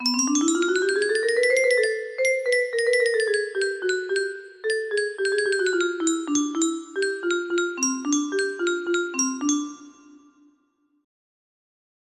Circus-music music box melody